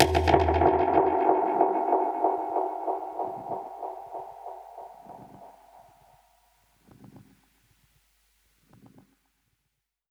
Index of /musicradar/dub-percussion-samples/95bpm
DPFX_PercHit_A_95-07.wav